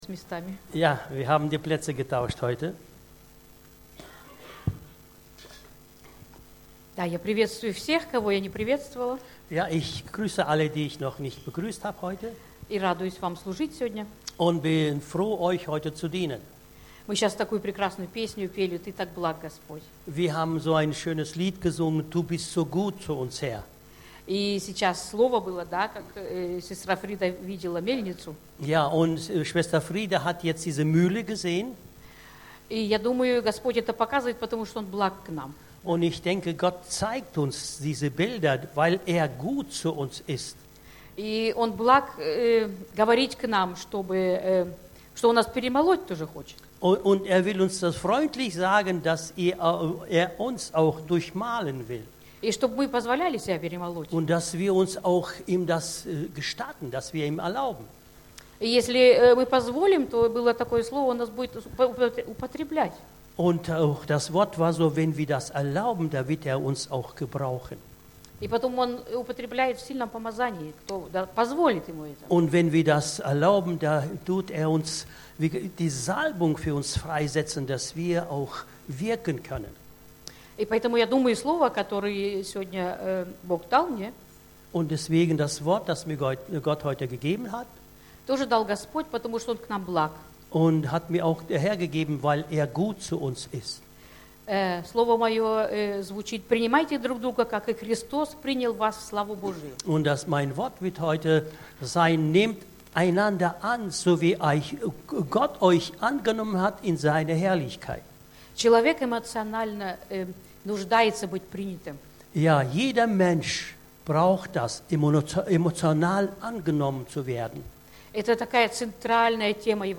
Predigten – Freie Evangeliums Christengemeinde Löningen